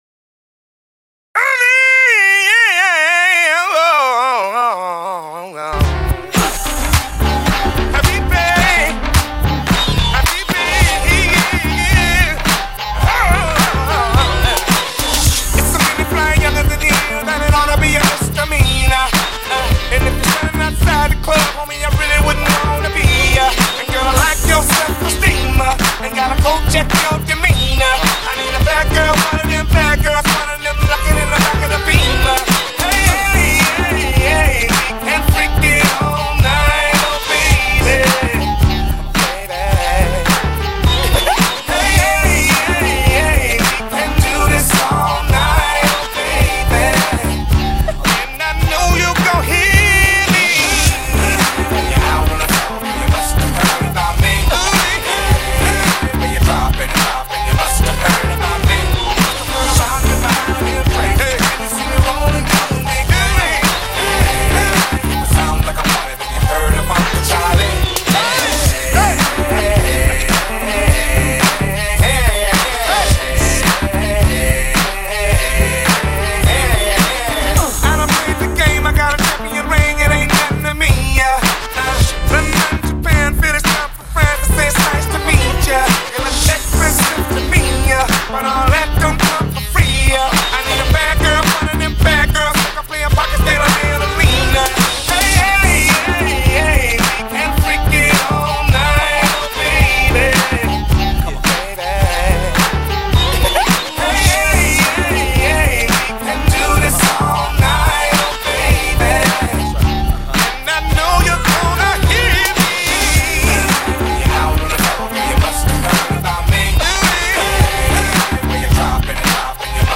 Old School RnB
Description: had to freak this song so you can dance.